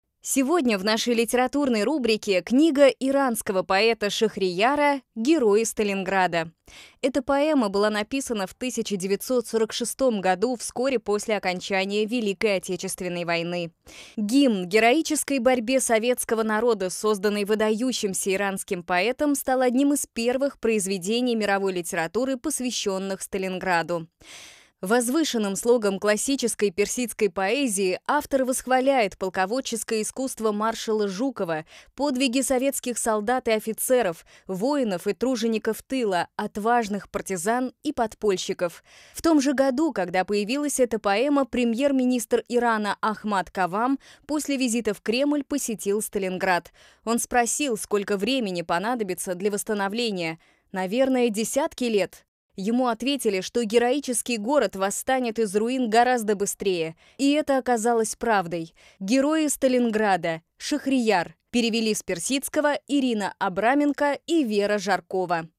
Аудиозапись радиостанции «Гордость»: